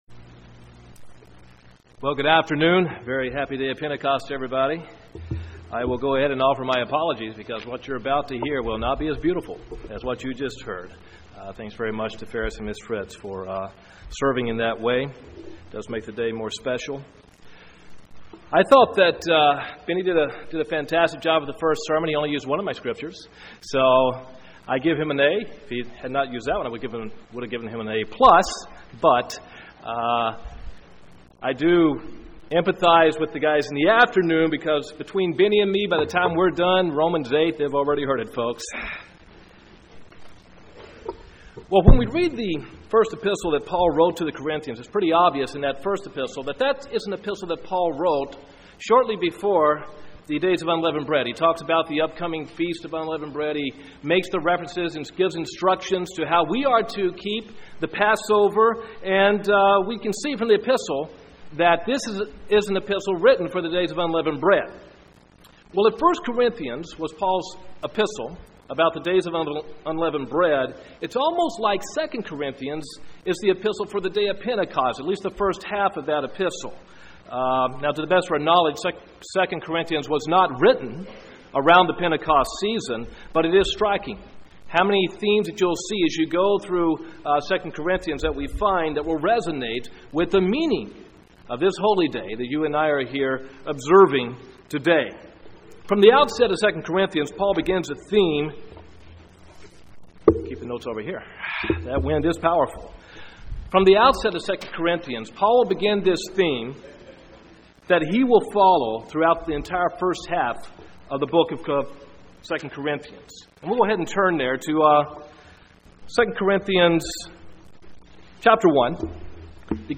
Given in Huntsville, AL
UCG Sermon Studying the bible?